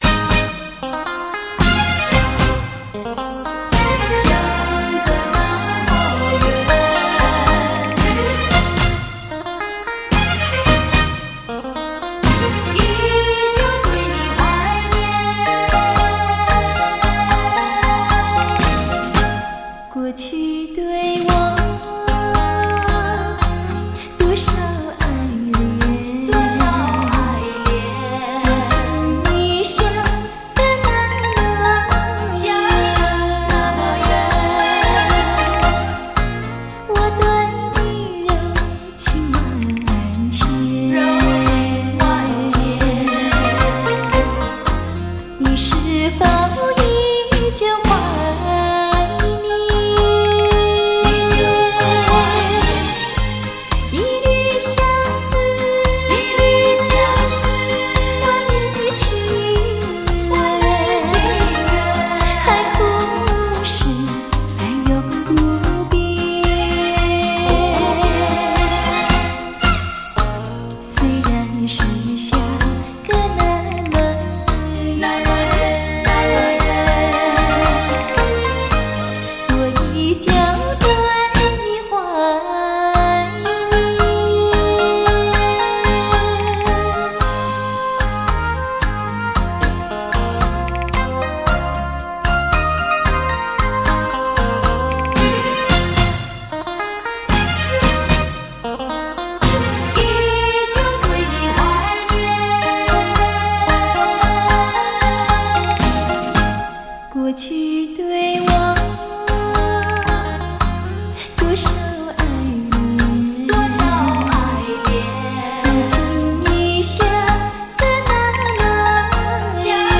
小调女皇 柔情贴心